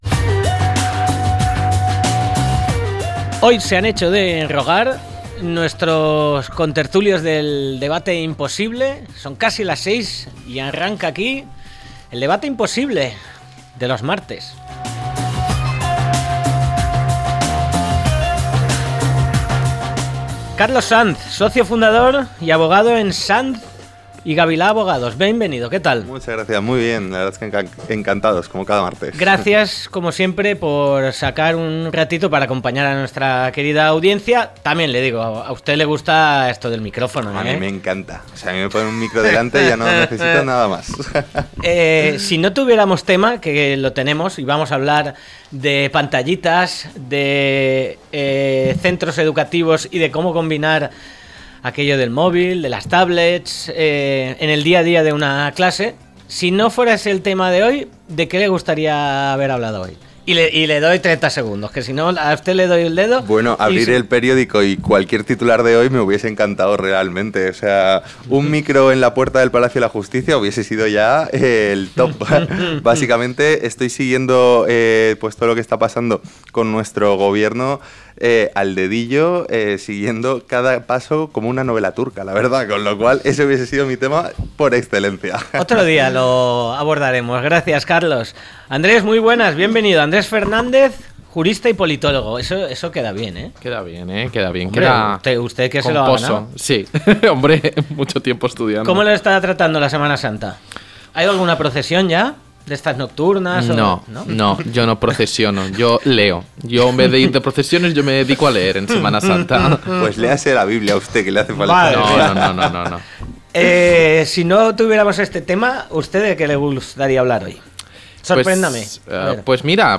Hoy lo ponemos sobre la mesa con nuestros invitados y, como siempre, con vuestras opiniones.